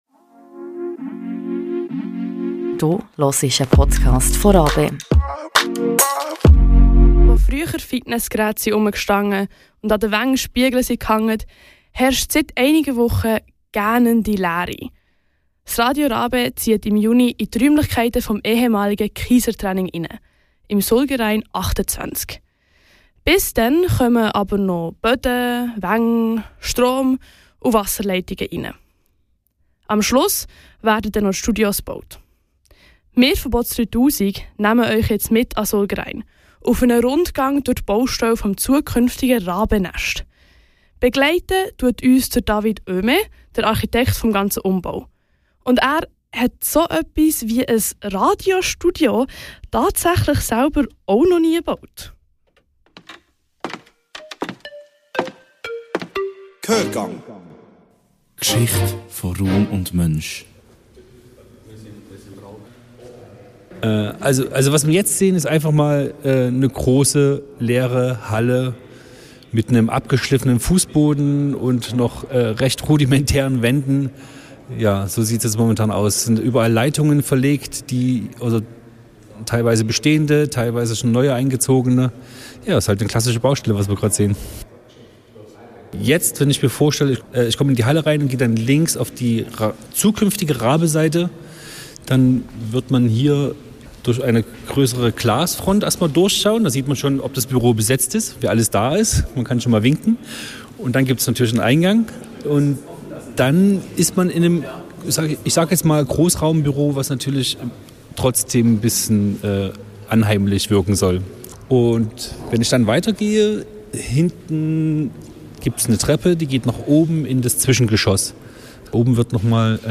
Gehörgang Baustelle des neuen RaBe Studios